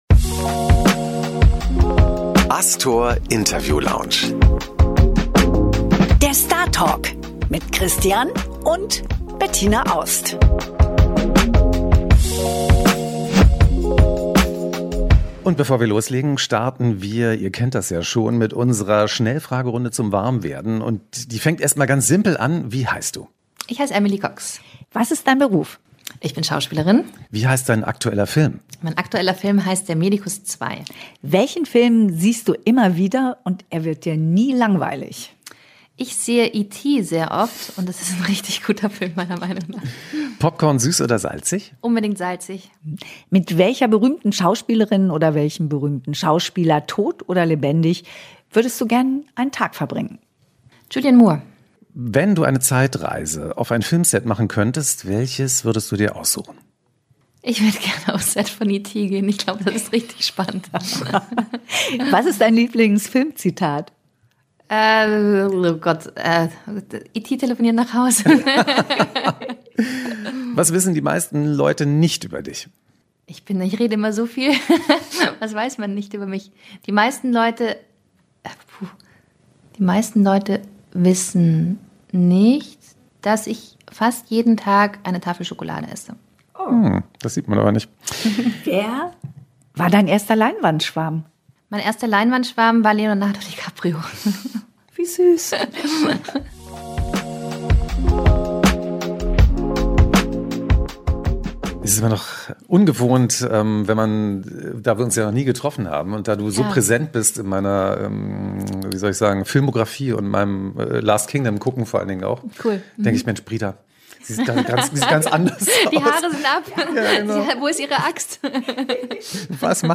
Emily Cox im Interview: Die Schauspielerin über ihren Film „Der Medicus 2“, Schwertkämpfe und eine Kindheit voller Musik.